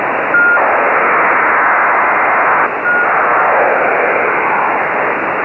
To find the active channels, monitor the frequencies in the pool of listed node frequencies until you hear the ground station data burst (which will occur roughly every 30 seconds).
HFDL.mp3